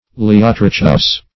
Search Result for " leiotrichous" : The Collaborative International Dictionary of English v.0.48: Leiotrichous \Lei*ot"ri*chous\ (-k[u^]s), a. [See Leiotrichi .]